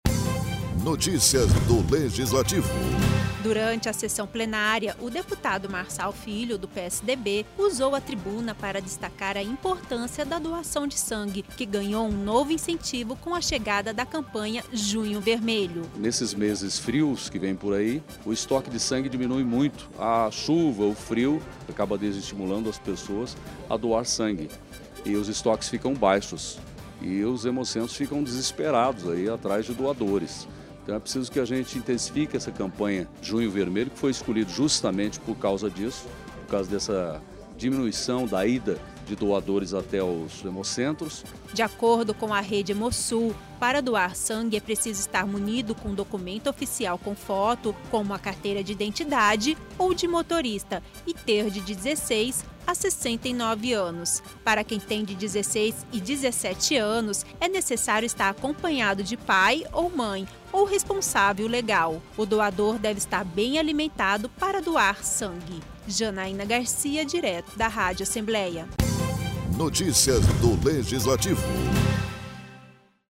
Nesta terça-feira, o deputado Marçal Filho, do PSDB usou a tribuna para destacar a importância da doação de sangue que ganhou um novo incentivo com a chegada da Campanha Junho Vermelho.